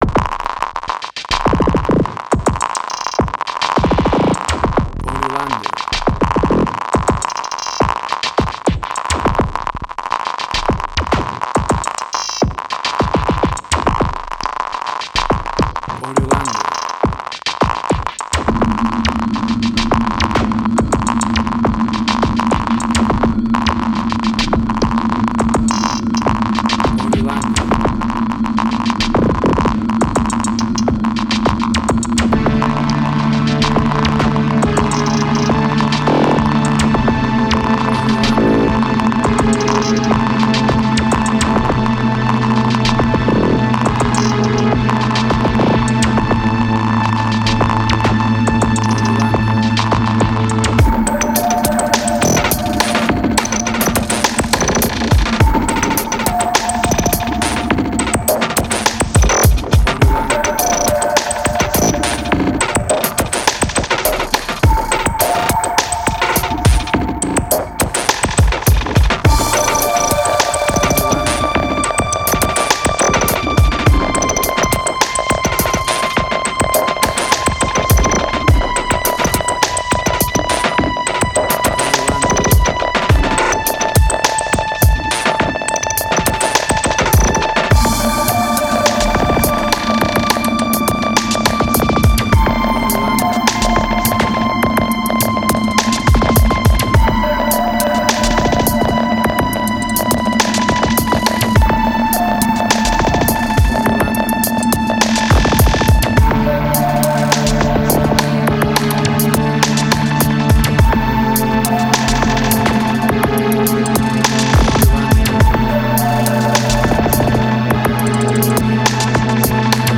IDM, Glitch.
Tempo (BPM): 104